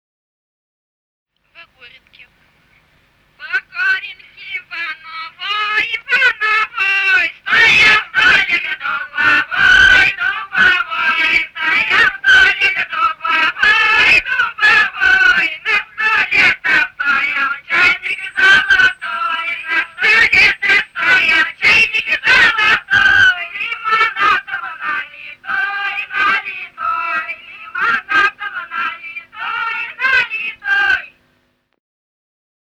Русские народные песни Владимирской области [[Описание файла::8а. Во горенке во новой (свадебная) д. Тереховицы Муромского района Владимирской области.